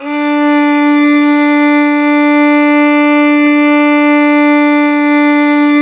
Index of /90_sSampleCDs/Roland L-CD702/VOL-1/STR_Violin 4 nv/STR_Vln4 _ marc
STR VLN BO05.wav